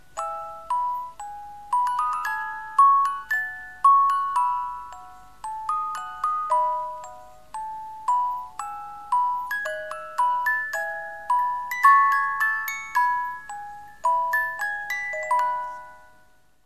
Boîte à musique...